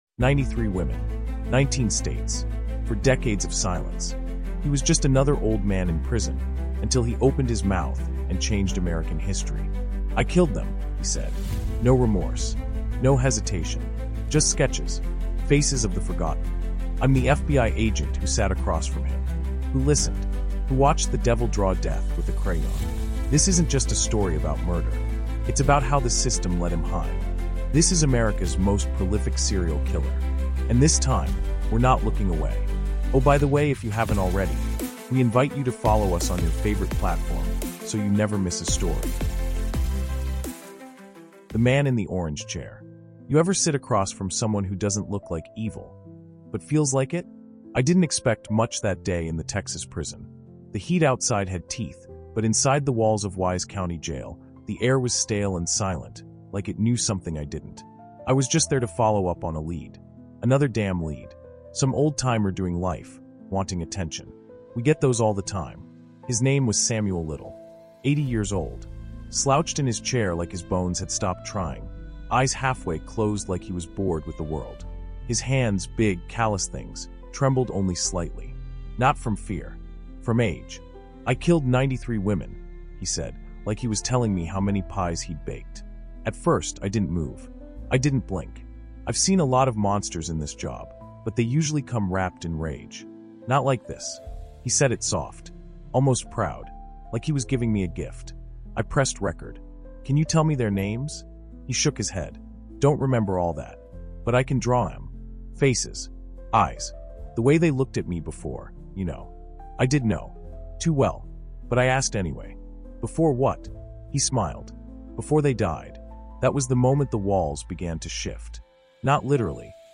America’s Deadliest Serial Killer Exposed | A Chilling True Crime Dive | AudioBook